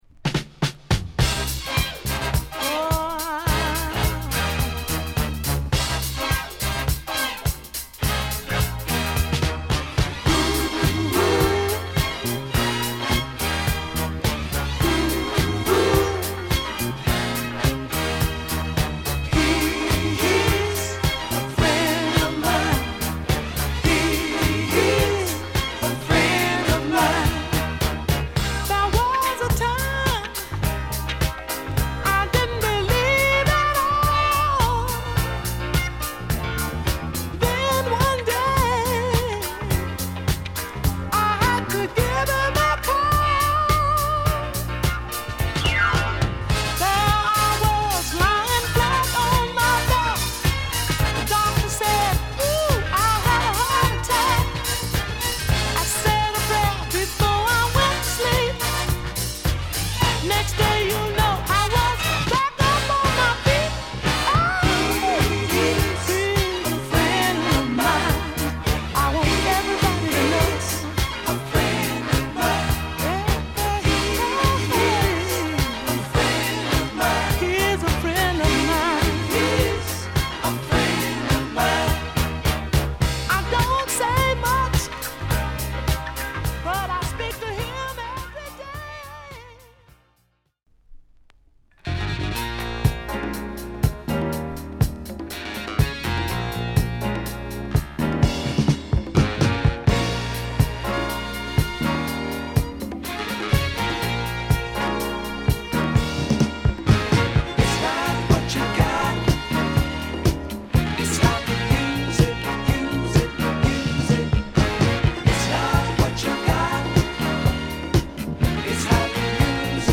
使える良質なディスコトラック多し！